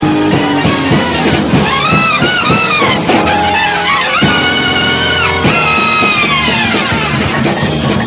Non-Album songs that are known to have been played live: